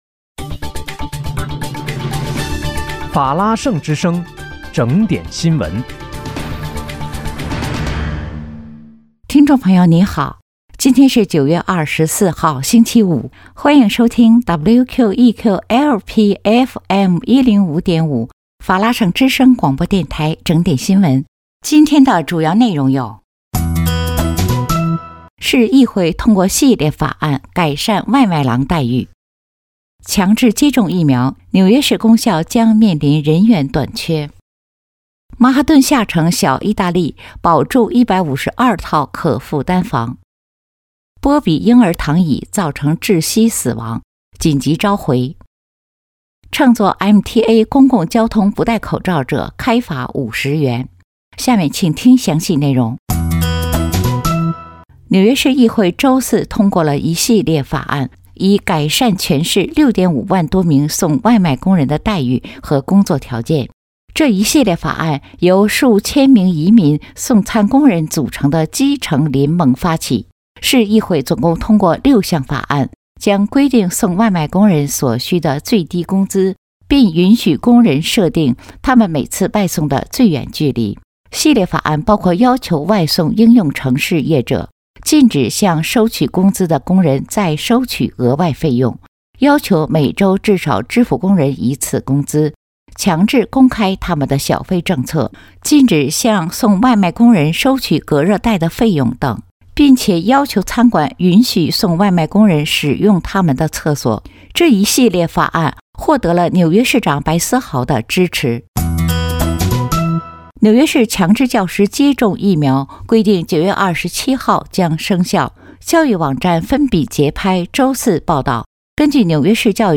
9月24日（星期五）纽约整点新闻